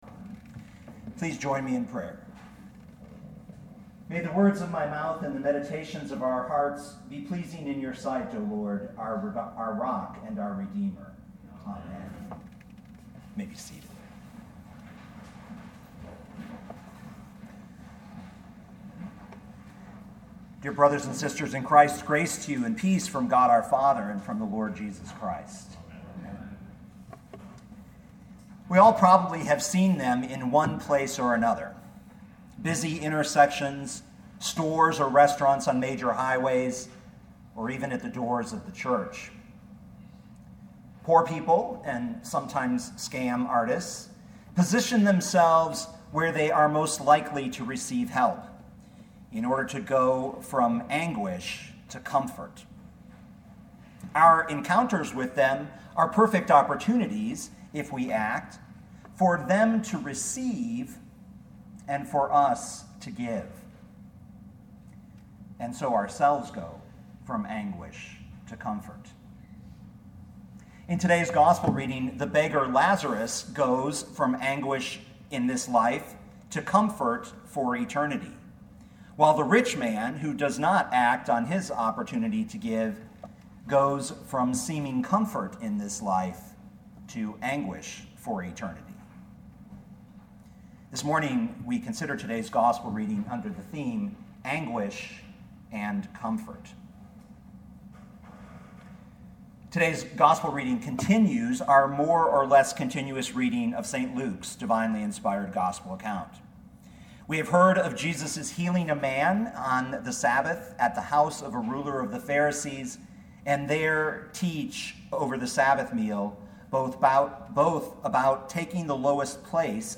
2016 Luke 16:19-31 Listen to the sermon with the player below, or, download the audio.